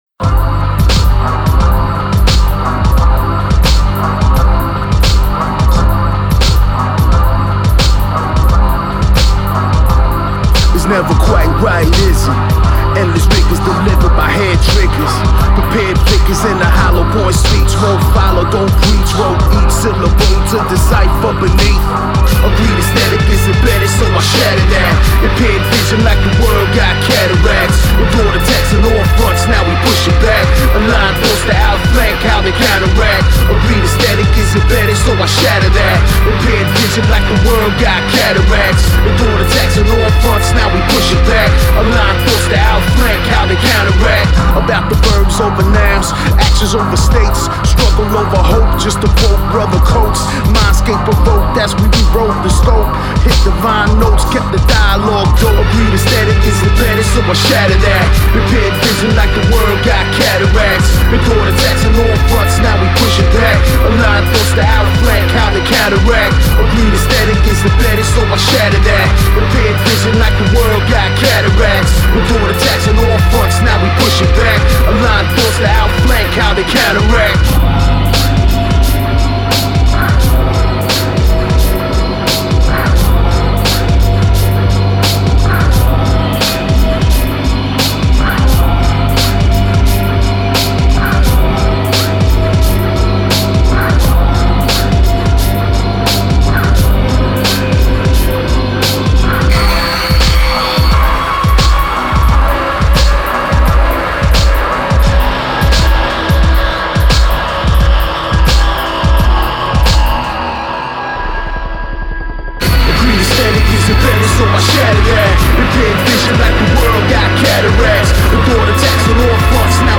Жанр: experimental hip hop.